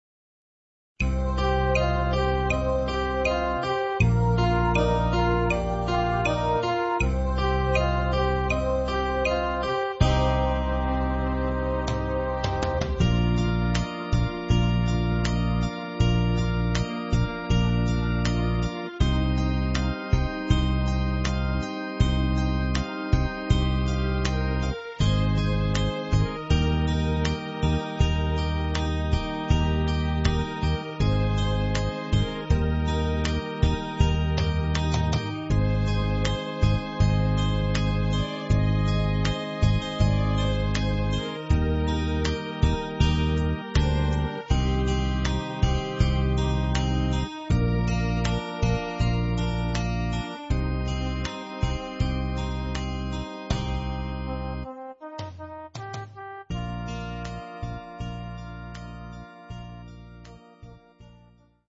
Pistas Instrumentales Católicas Midi y Mp3